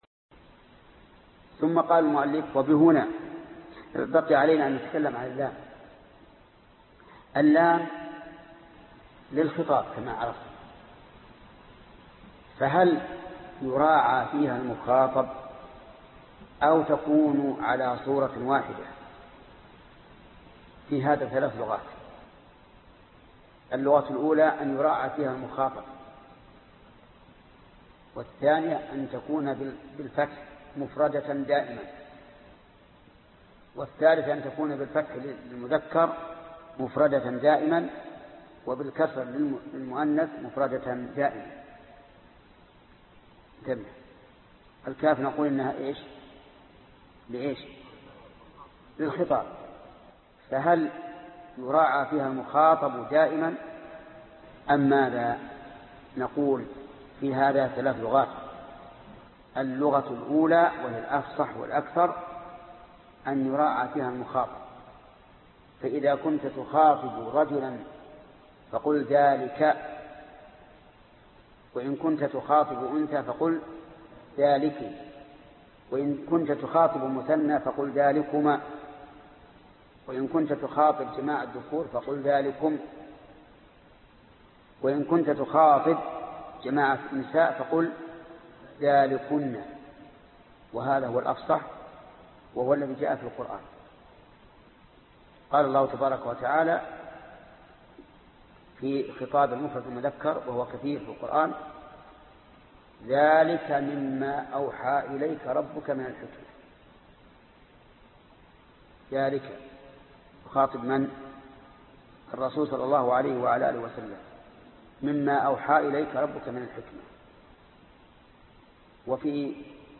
الدرس 63 ( شرح الفية بن مالك ) - فضيلة الشيخ محمد بن صالح العثيمين رحمه الله